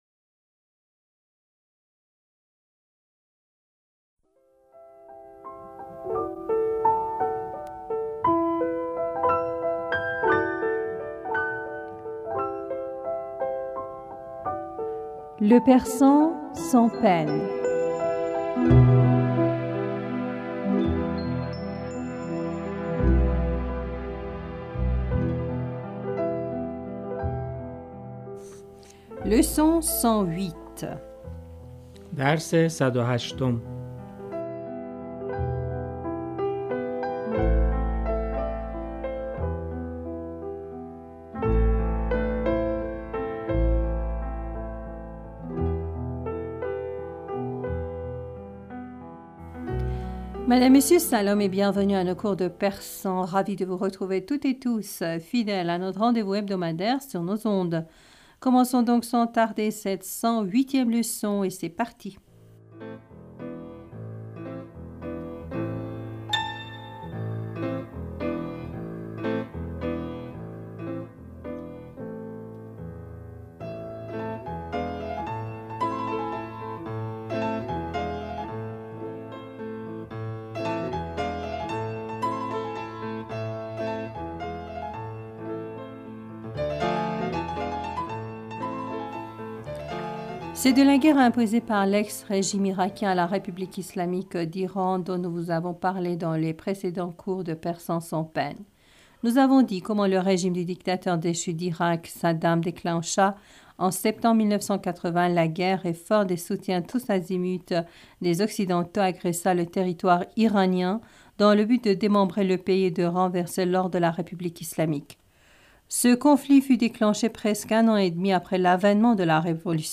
Madame, Monsieur Salam et bienvenue à nos cours de persan.